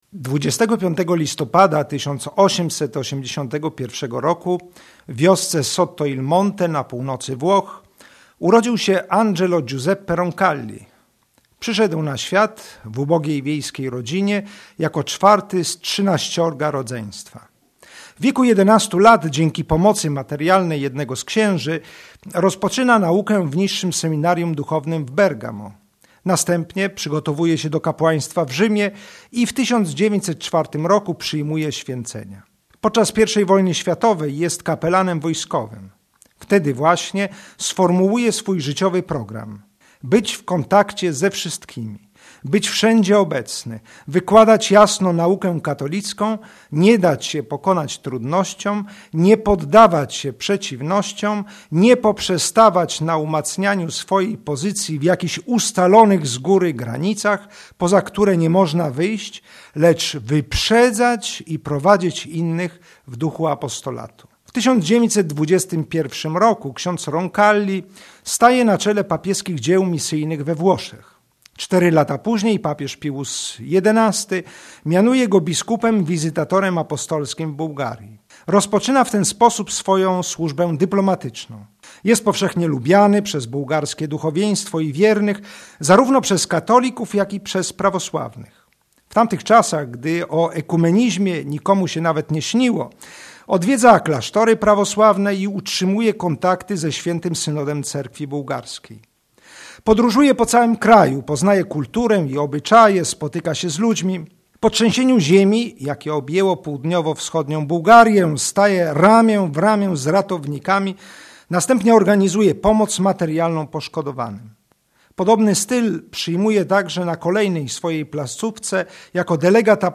Katecheza bp. Krzysztofa Nitkiewicza wygłoszona na antenie Radia Maryja